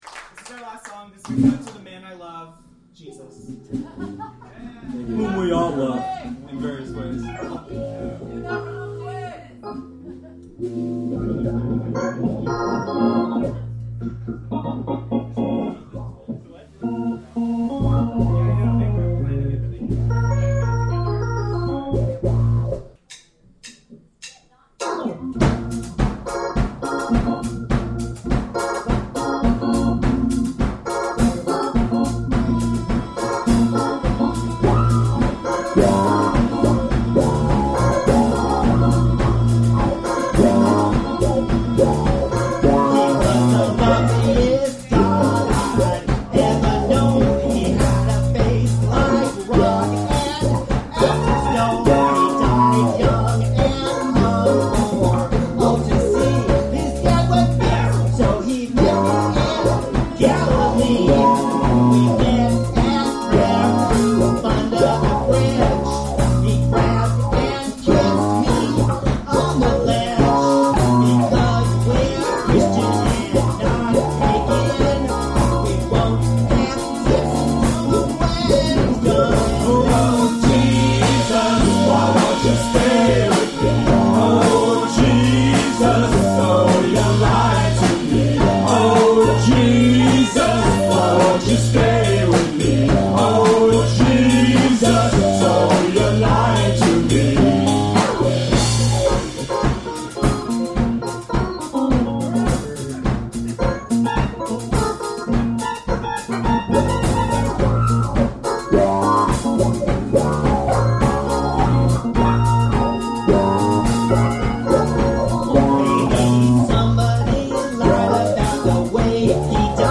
At the Fish Tank, 2002